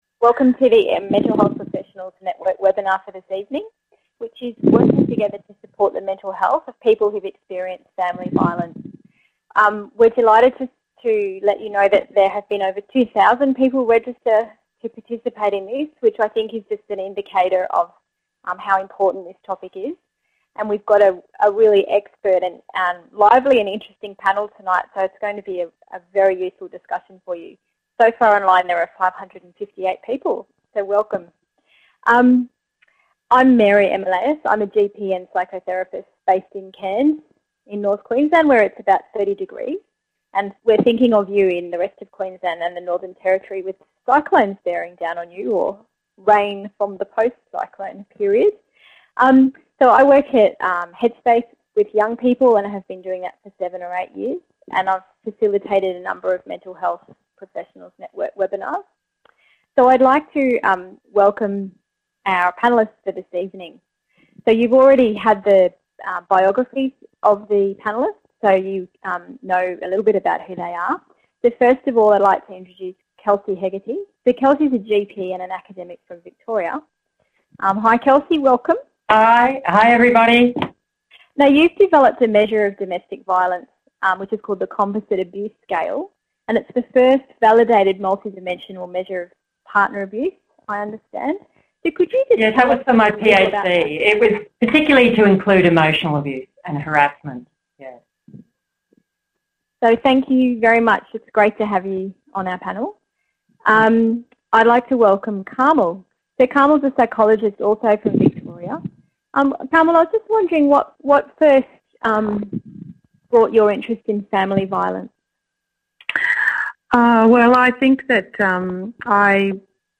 Join our interdisciplinary panel of experts for a discussion on how to best support the mental health of people who have experienced family violence.